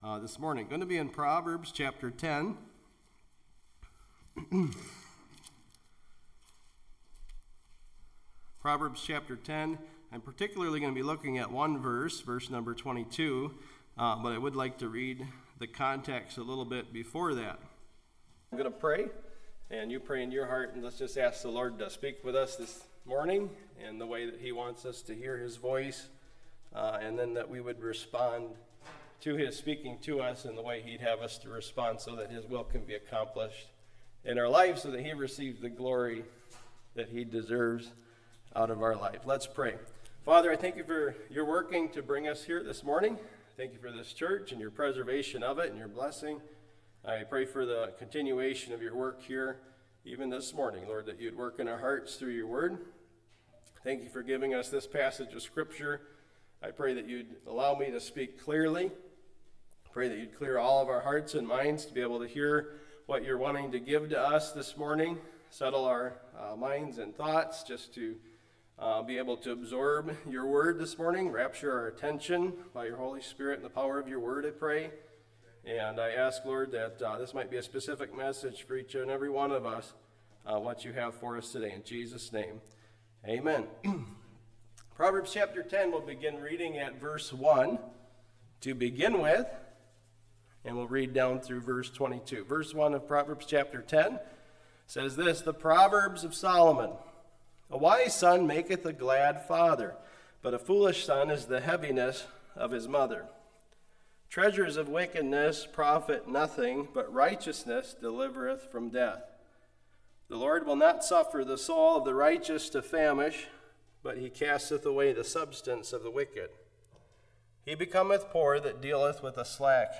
Bethlehem Baptist Church is a vibrant, growing, Great-Commission focused church located in Viroqua, WI.